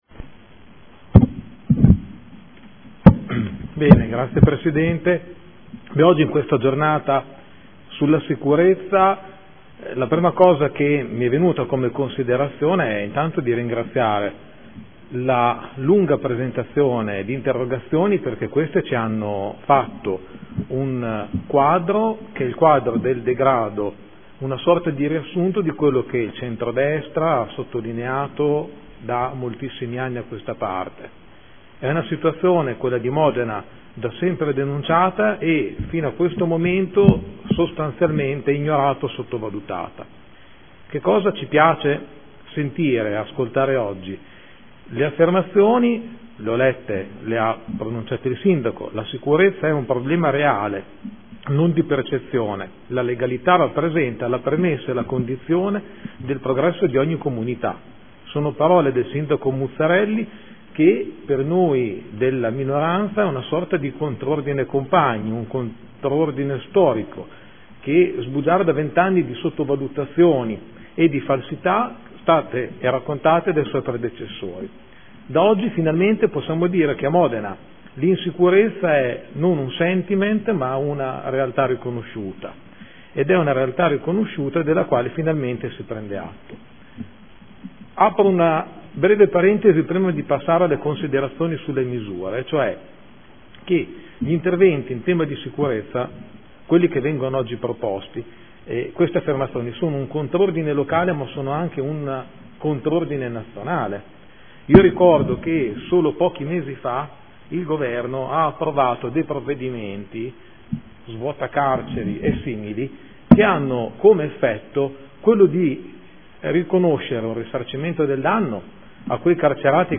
Seduta del 9/10/2014 Dibattito Sicurezza.